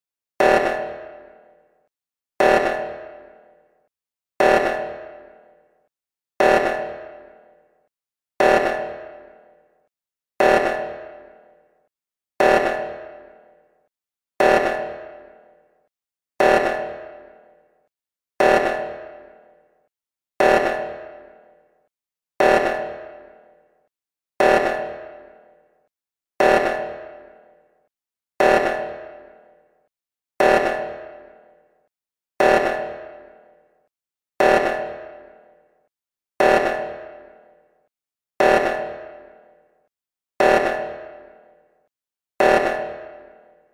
Звук тревоги при саботаже в Among Us